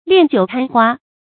發音讀音
成語簡拼 ljth 成語注音 ㄌㄧㄢˋ ㄐㄧㄨˇ ㄊㄢ ㄏㄨㄚ 成語拼音 liàn jiǔ tān huā 發音讀音 常用程度 一般成語 感情色彩 貶義成語 成語用法 作謂語、定語；指貪婪酒色 成語結構 聯合式成語 產生年代 古代成語 近義詞 戀酒迷花 、 戀酒貪色 成語例子 明·汪廷訥《獅吼記·諫柳》：“季常此后決不敢 戀酒貪花 ，但下官有一言奉告。”